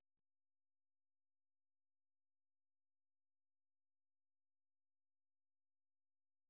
Форма симфоническая поэма
Произведение написано в тональности ре минор и является примером ранних тональных работ Шёнберга.